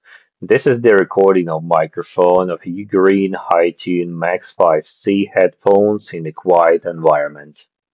Microphone
The microphone in the UGREEN HiTune Max5c is pretty good, a 7 out of 10, made at a fairly high level both in class and overall.
mic-lownoise-en.mp3